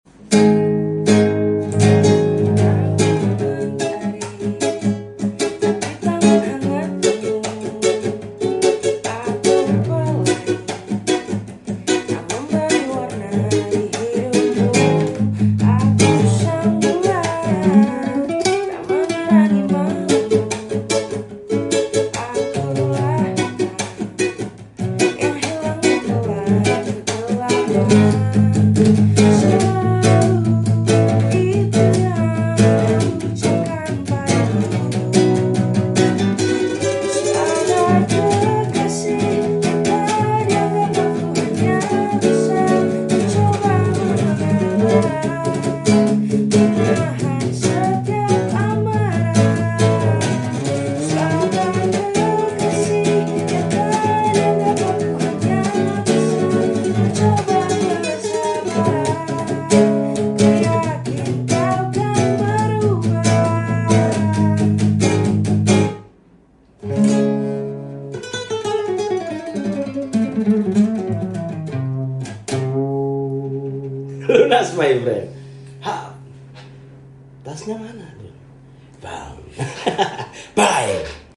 Versi Reggae Pop Punk Gitar Nylon.